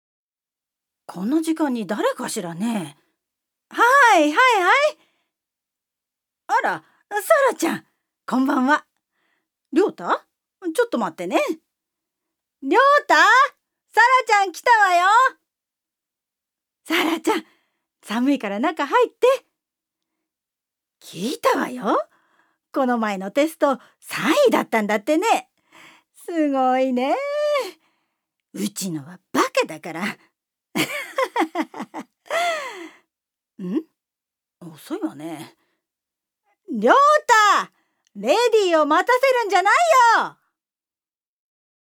ジュニア：女性
セリフ３